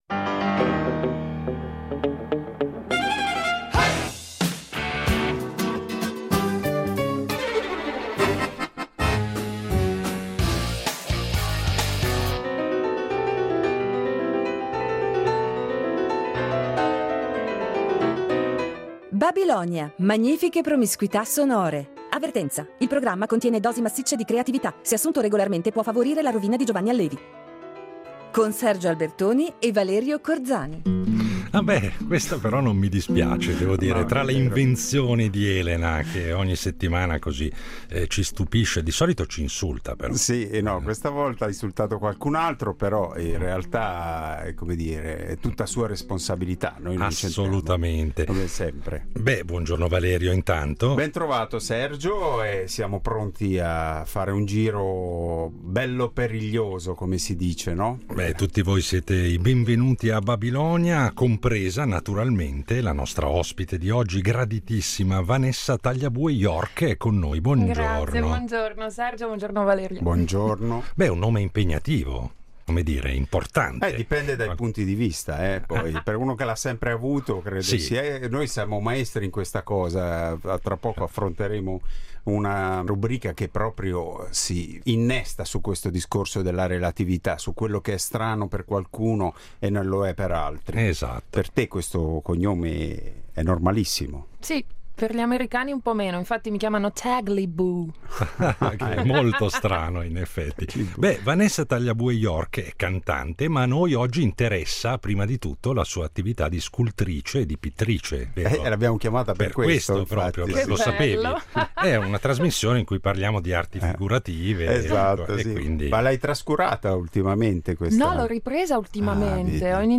L’elenco dei suoi lavori potrebbe continuare, ma ne parleremo con lei tra un ascolto sbalestrante e l’altro della nostra babilonica scaletta.